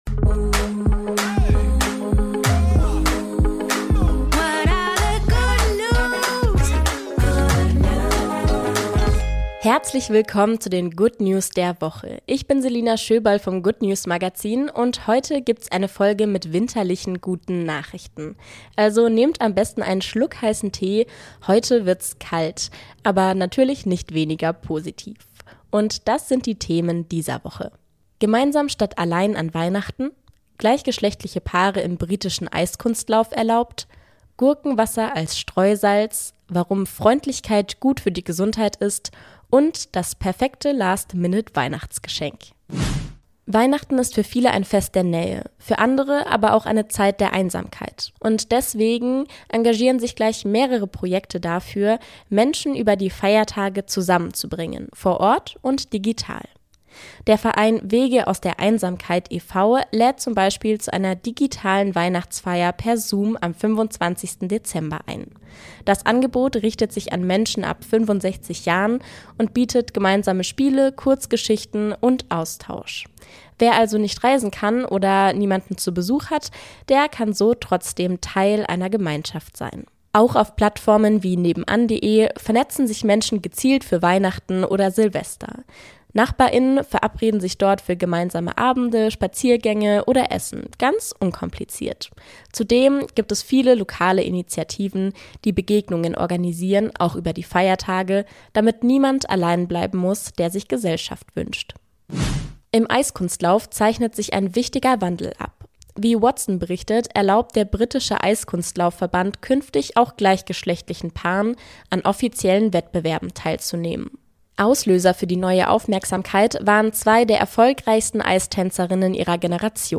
Eine kleine Portion Good News für die Ohren.